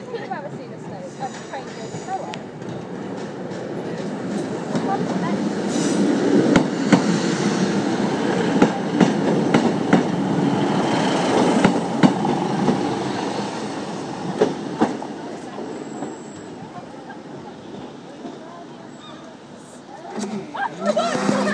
Train goes by boo